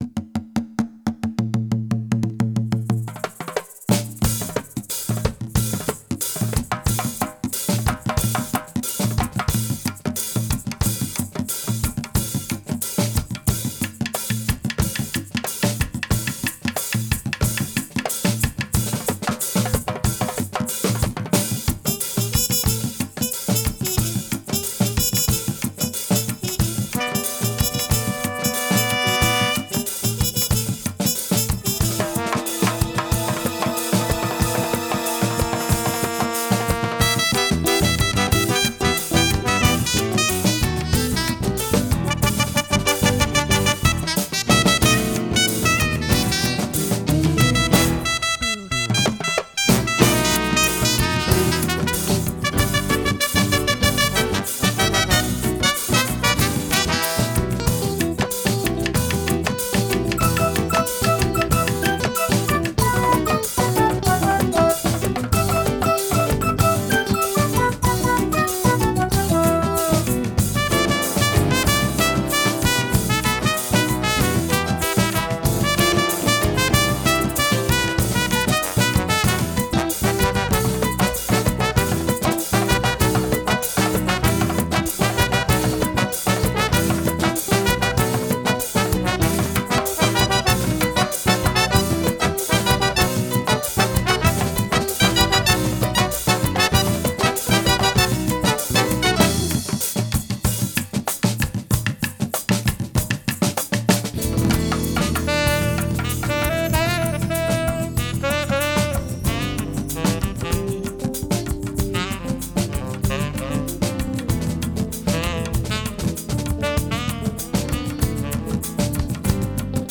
La música de América Latina